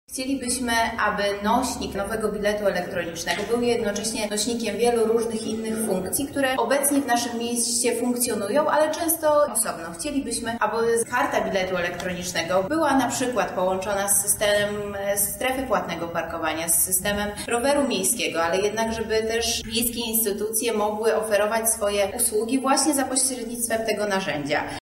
O tym jakie funkcje miałaby mieć Lubelska Karta Mieszkańca mówi radna Marta Wcisło: